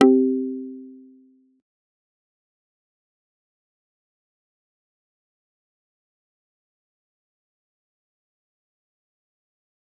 G_Kalimba-C4-pp.wav